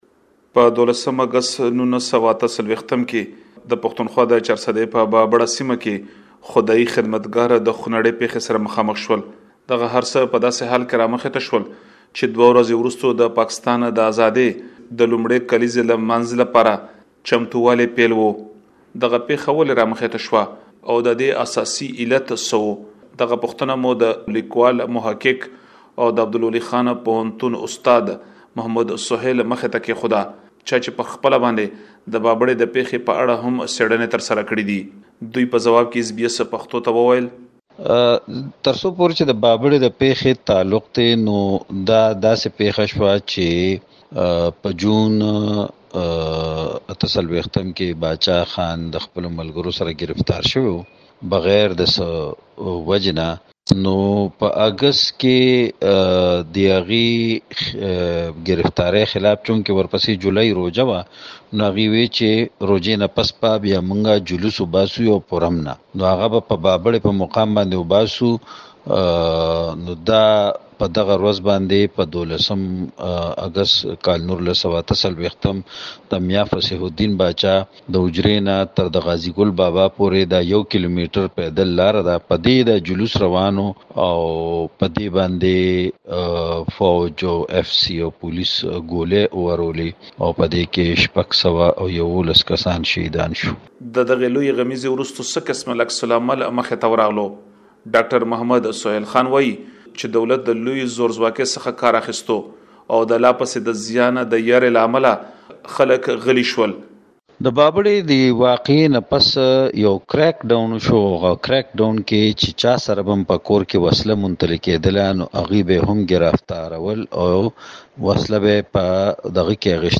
یو رپوټ